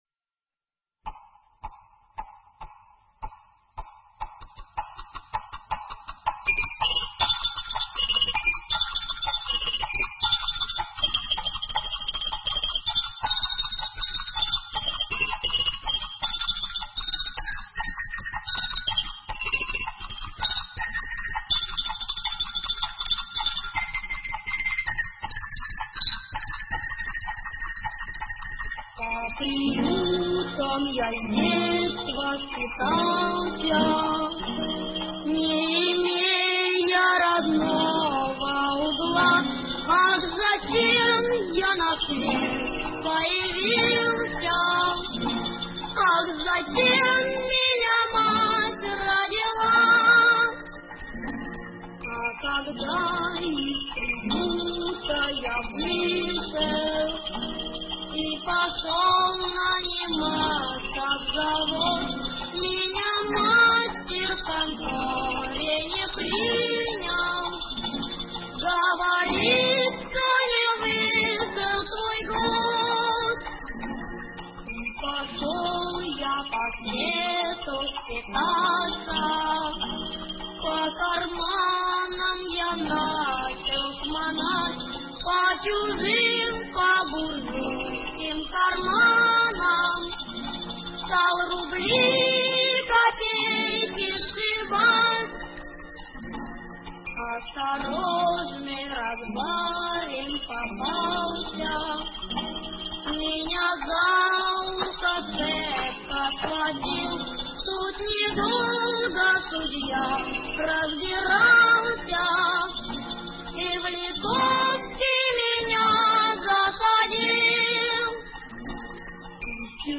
Фонограмма из кинофильма